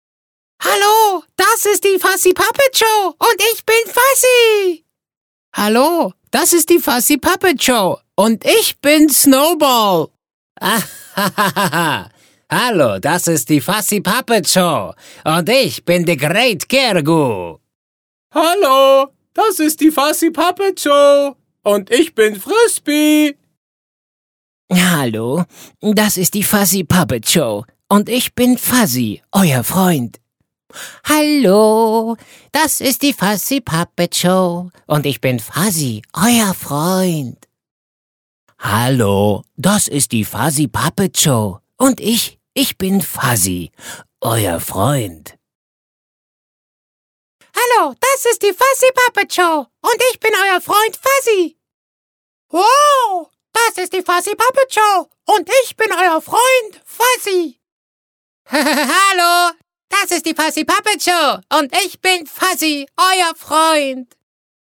Male
Yng Adult (18-29), Adult (30-50)
All Styles -Deep to high - Heavy to warm.
Character / Cartoon
All our voice actors have professional broadcast quality recording studios.
1206CartoonVoices.mp3